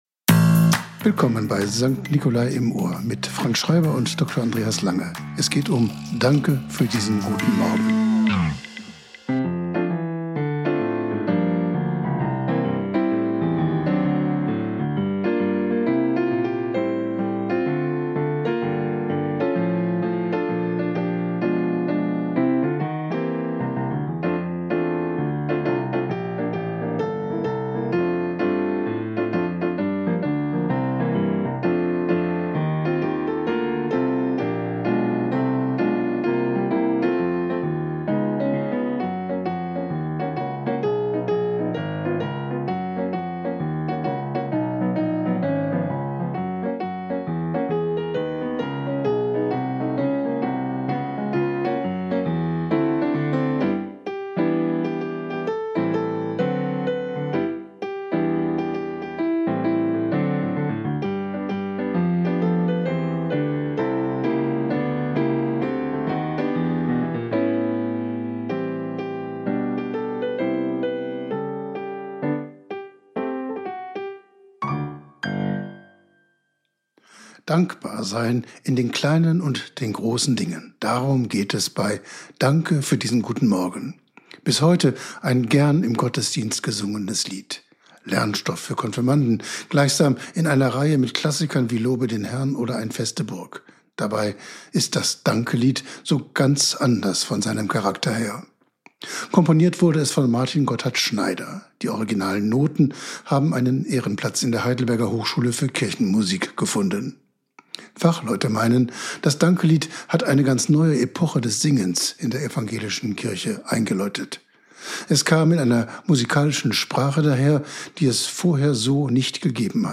Wort und Musik aus der St. Nicolai-Kirche Lemgo